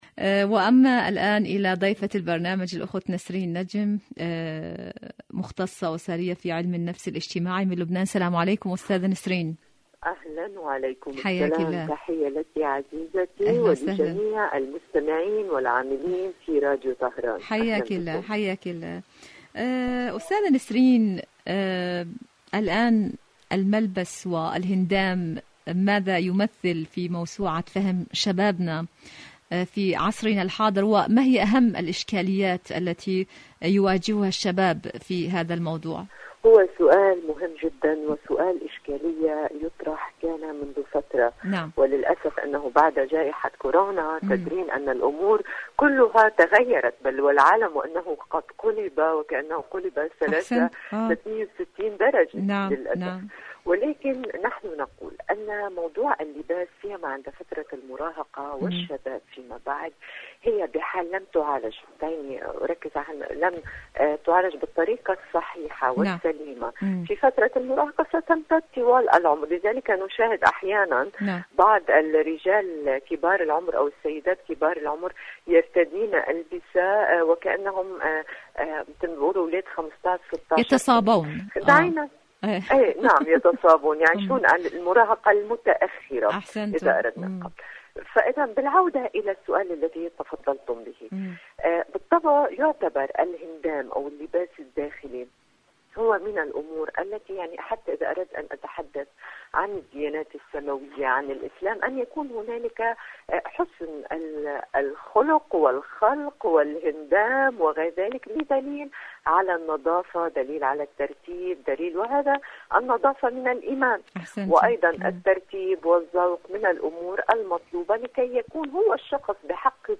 مقابلات برامج إذاعة طهران برنامج دنيا الشباب الشباب اختيار الملبس مقابلات إذاعية الملبس أنا أختار ملبسي ولكن..